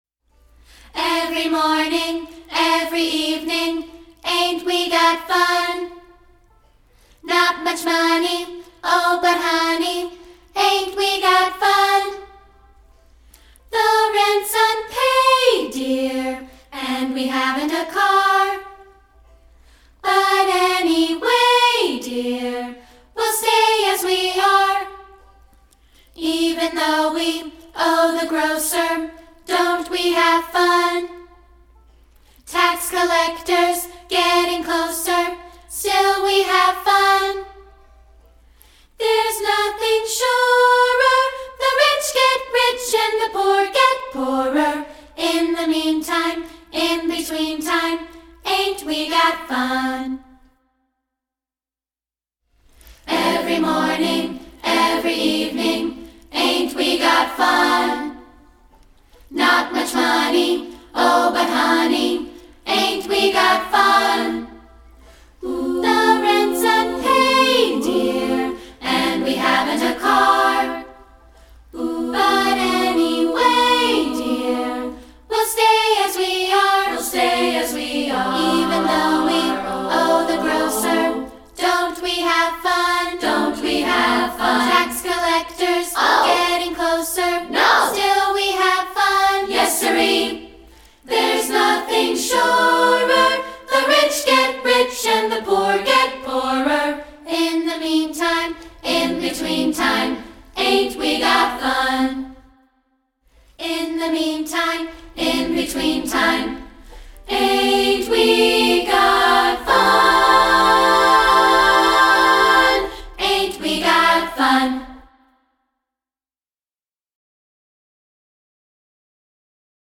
- A Cappella Version
Here's an a cappella version of the song.